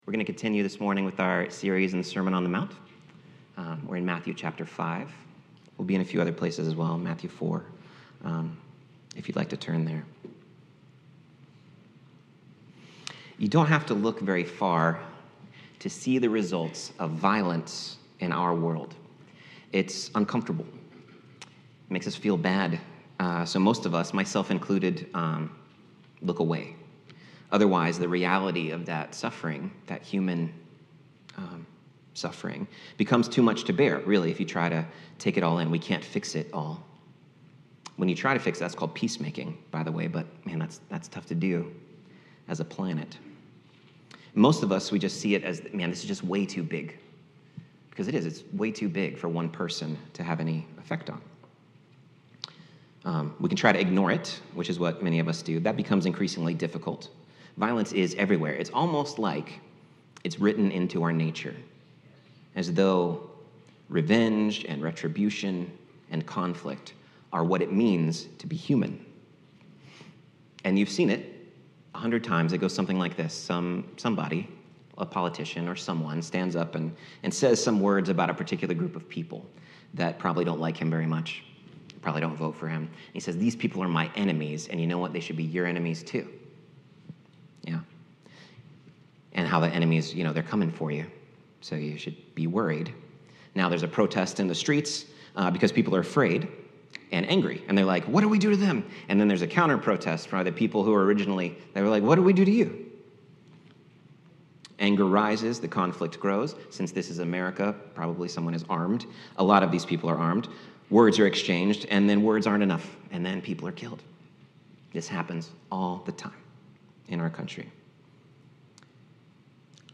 Sermons | Alliance church of Zephyrhills